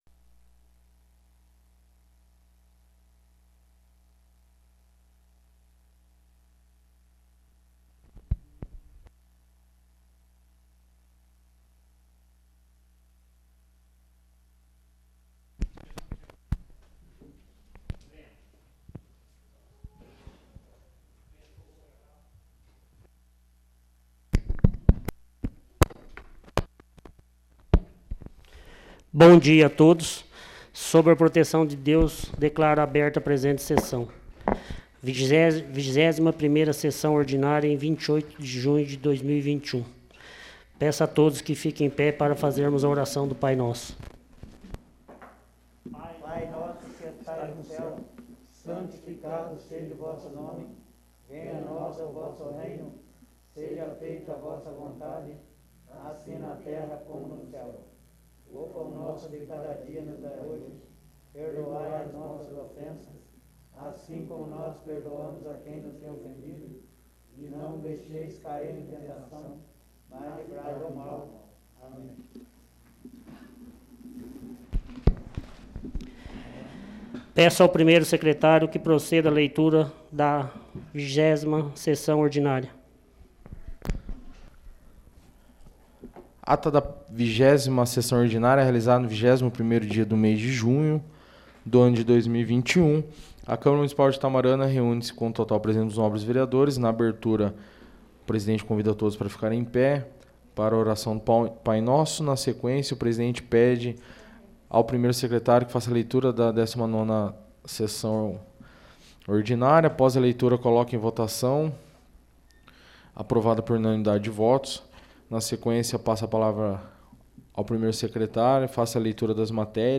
21ª Sessão Ordinária